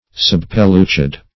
Subpellucid \Sub`pel*lu"cid\, a. Somewhat pellucid; nearly pellucid.